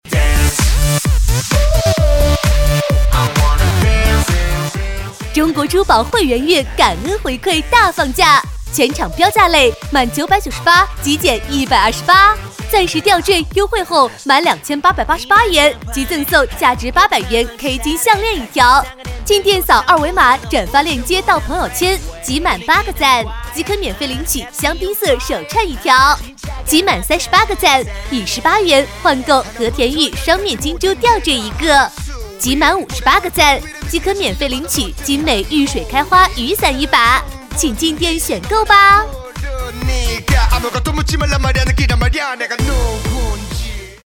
女国语129